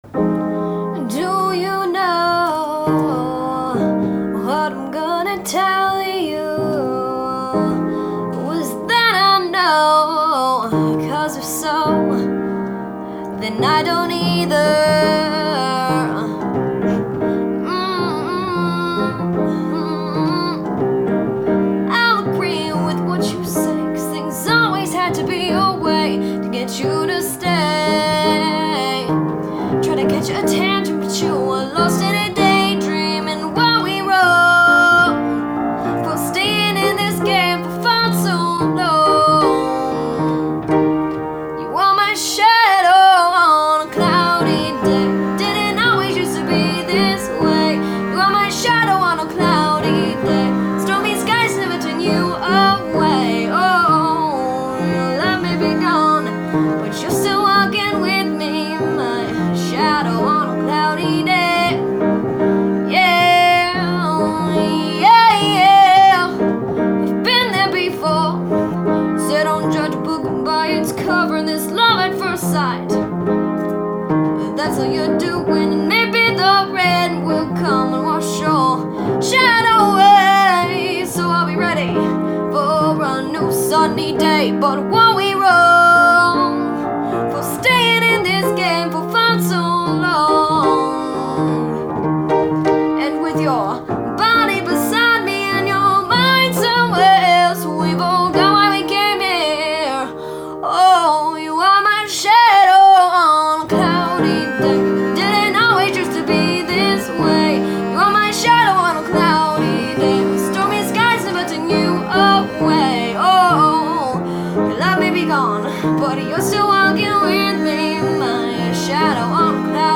piano
cello